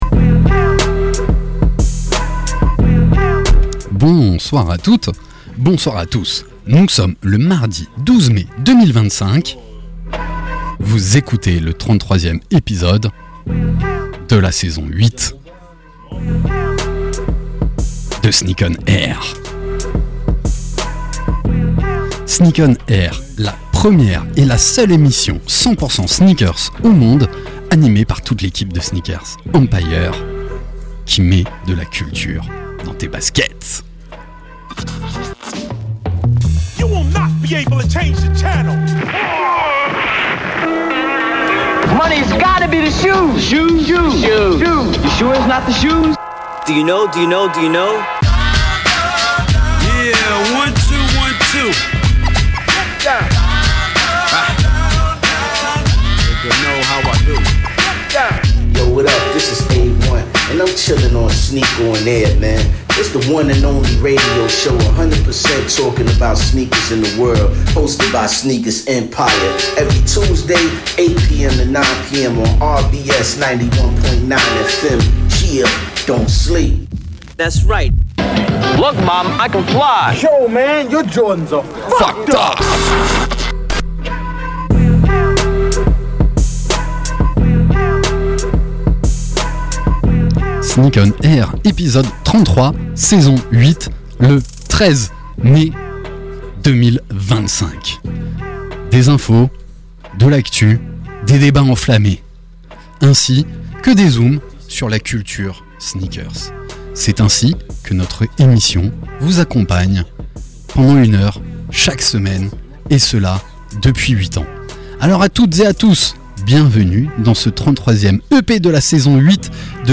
Sneak ON AIR, la première et la seule émission de radio 100% sneakers au monde !!! sur la radio RBS tous les mardis de 20h à 21h. Animée par l’équipe de Sneakers EMPIRE.
Pour cet épisode, nous vous proposons une heure de talk, d’actus, et de débats autour des faits marquants de l’univers de la sneaker avec tous nos chroniqueurs.